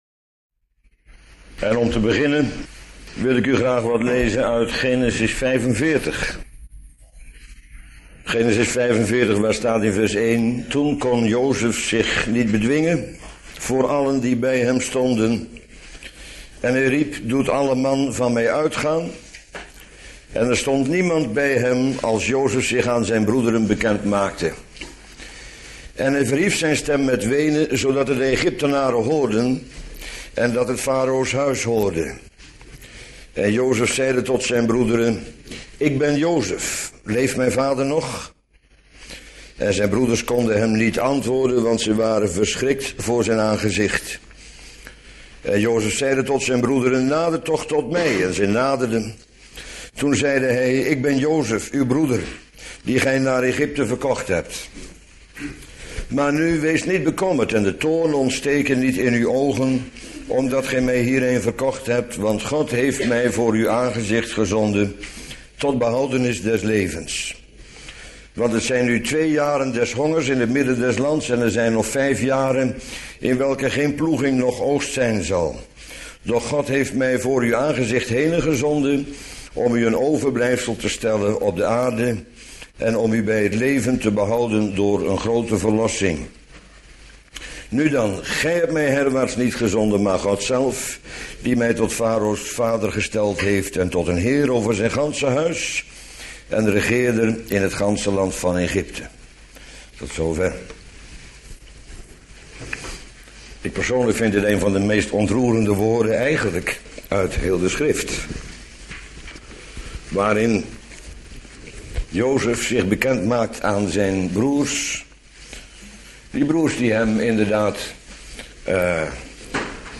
Bijbelstudie lezing